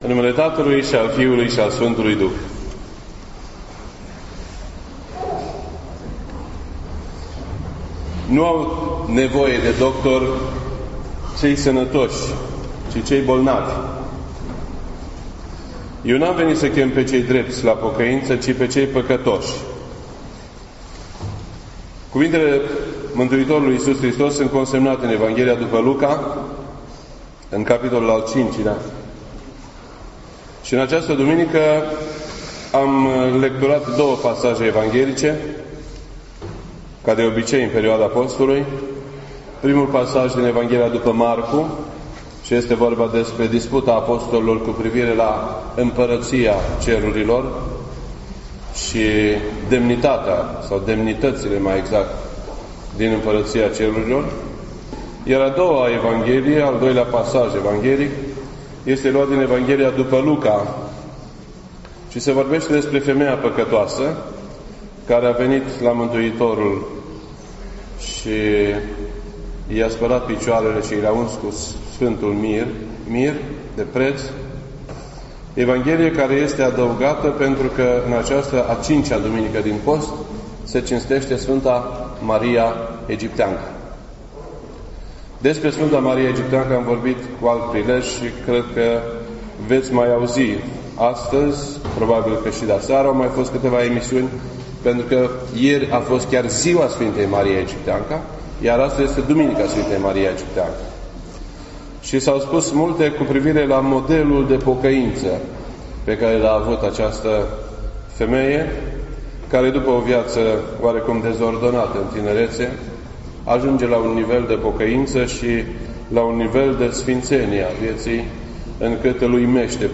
This entry was posted on Sunday, April 2nd, 2017 at 7:01 PM and is filed under Predici ortodoxe in format audio.